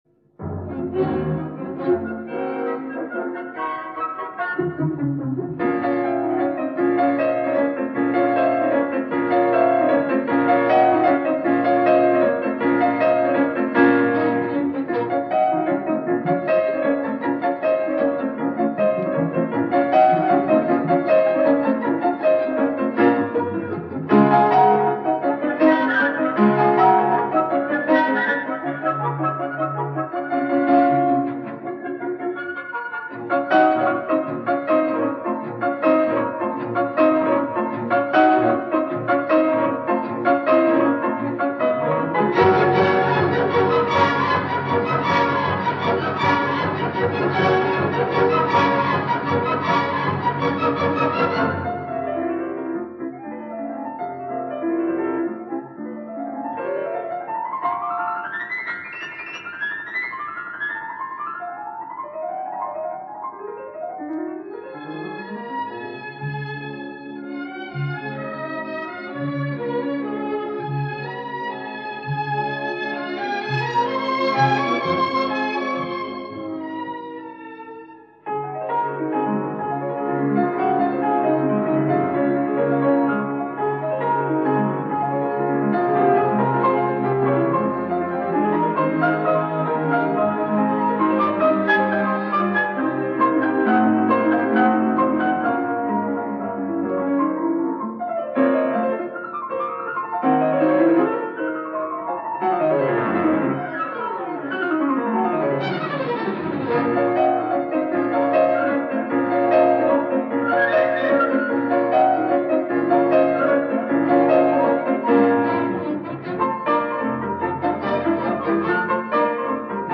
他的演奏非常细致而动人
充满了诗意般的美妙之情